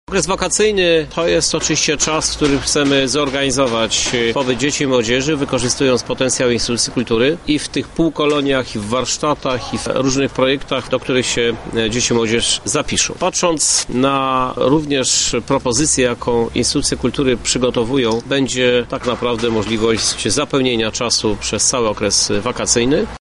Do udziału w akcji zachęca sam Prezydent Miasta Krzysztof Żuk: